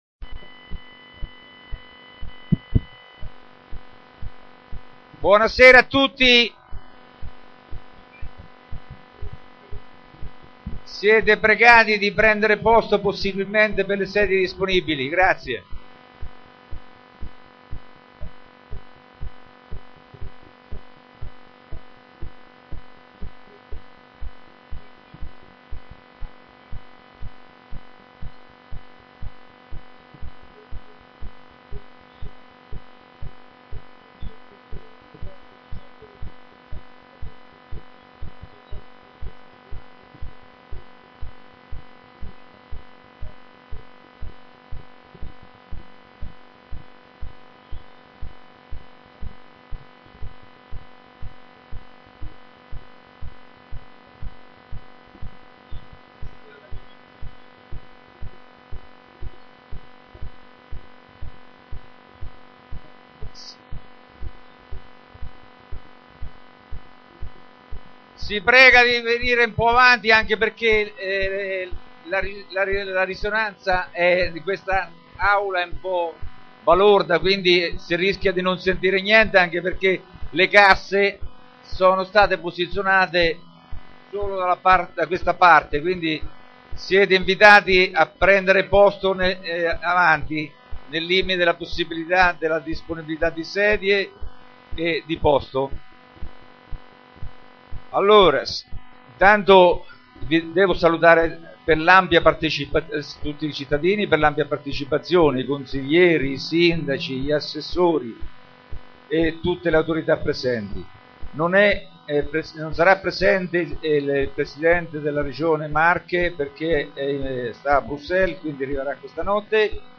Consiglio Comunale Aperto sui problemi occupazionali giovedi 3 dicembre 2009 ore 15.30 Oratorio della Carita`
Consiglio Comunale Aperto